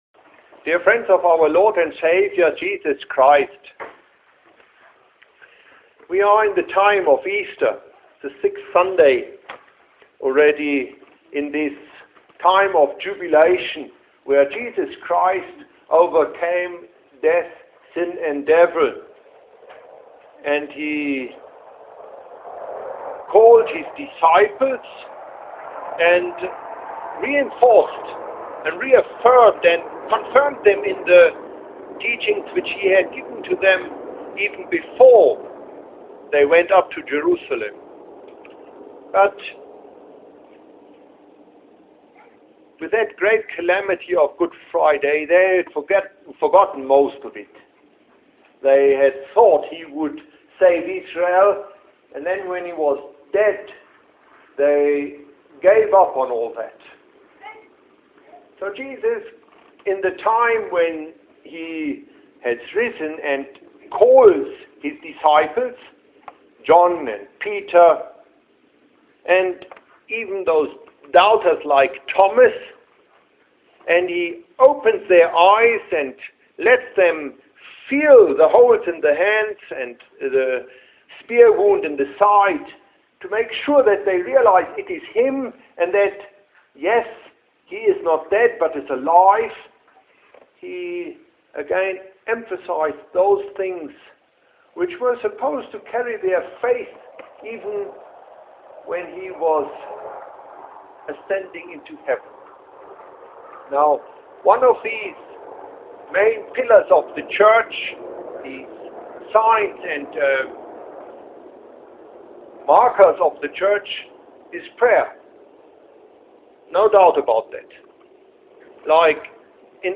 I preached at LTS in Tshwane (Pretoria) on this word of God too.